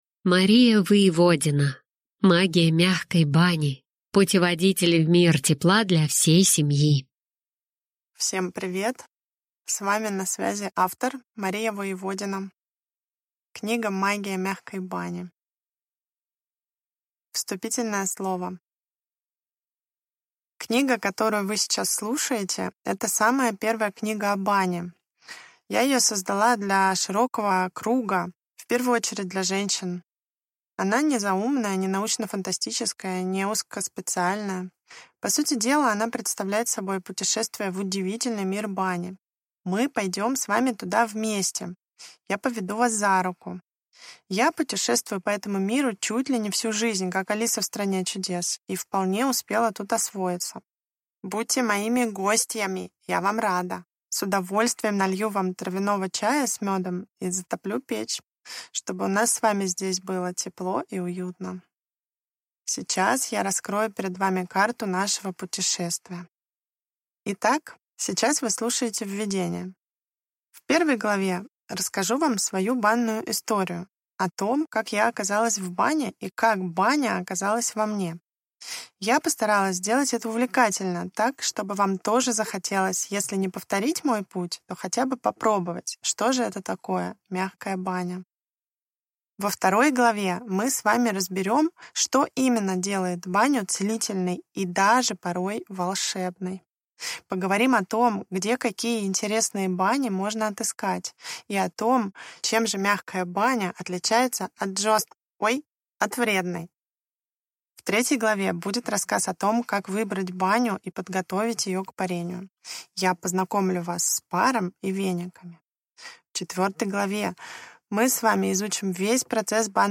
Аудиокнига Магия мягкой бани. Путеводитель в мир тепла для всей семьи | Библиотека аудиокниг